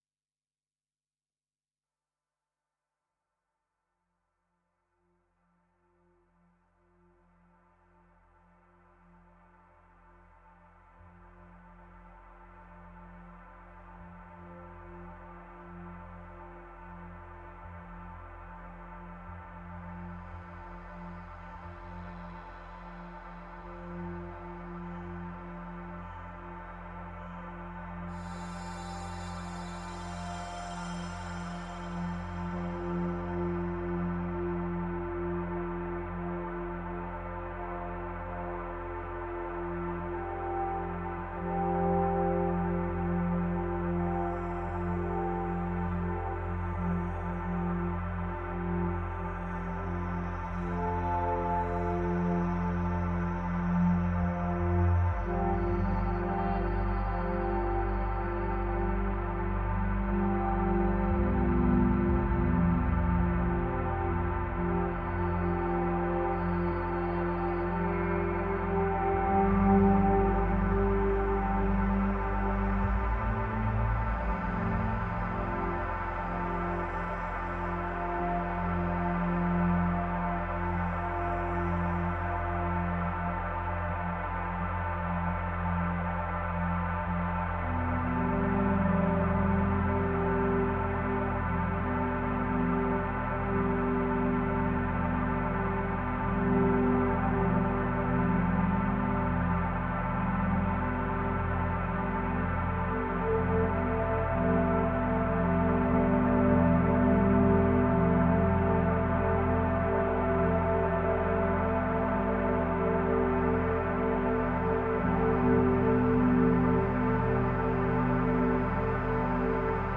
Modular synthesizers, pads, textures.
Music that leaves space and stretches time.